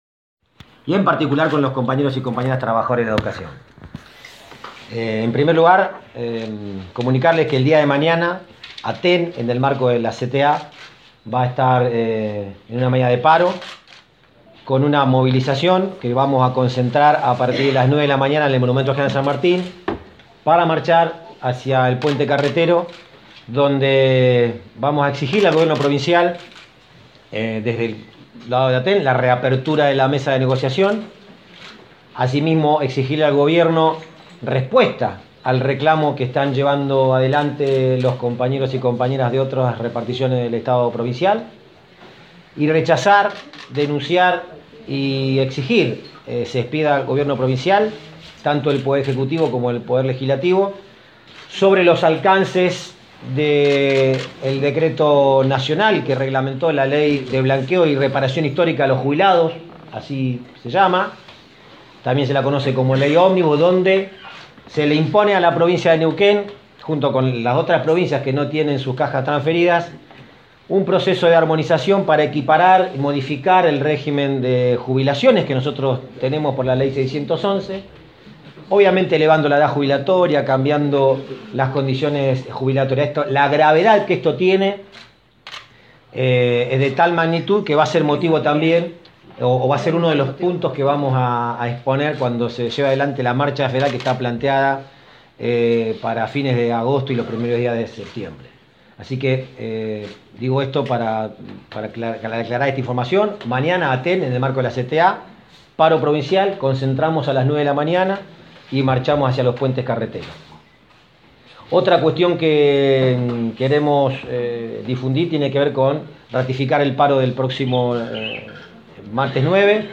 A continuación reproducimos el audio de la conferencia de prensa brindada en Aten provincial en el transcurso de la mañana de hoy. Allí se anuncia, entre otras cosas, la medida de fuerza para mañana -2 de agosto- en el marco de la CTA.
Conferencia de Prensa (1 de Agosto)